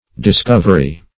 Discovery \Dis*cov"er*y\, n.; pl. Discoveries.